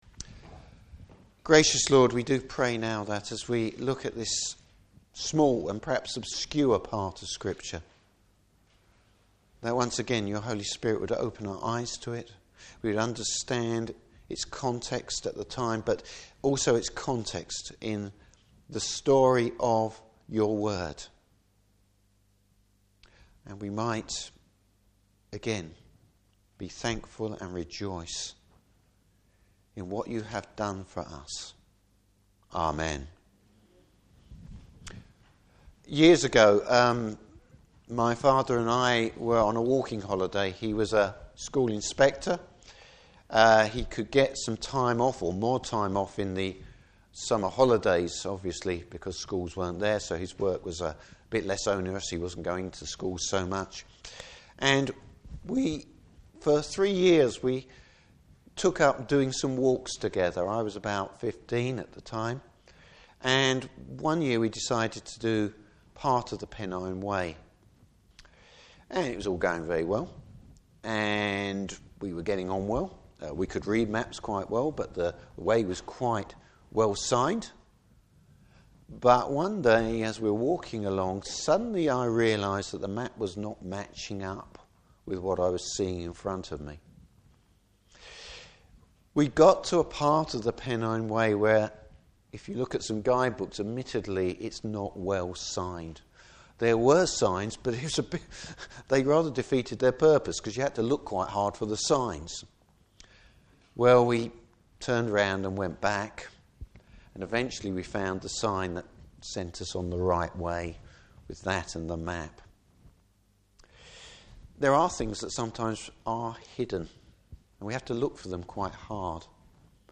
Service Type: Good Friday Service.